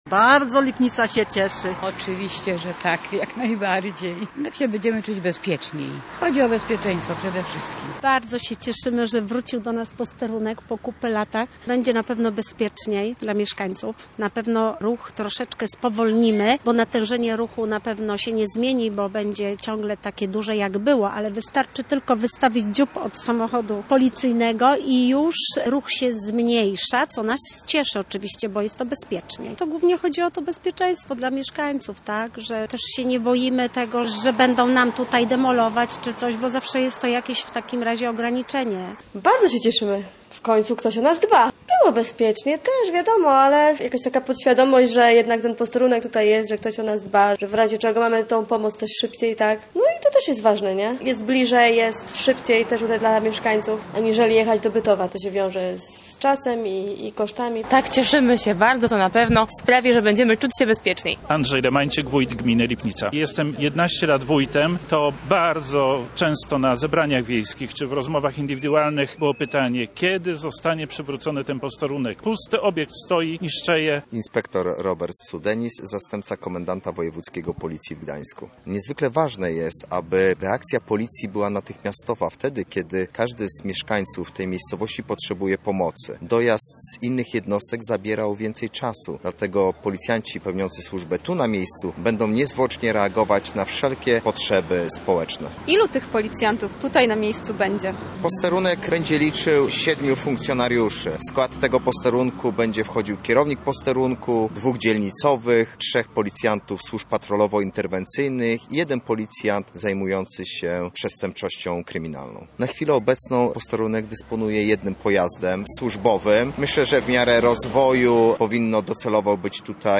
Zapraszamy do wysłuchania materiału naszej reporterki: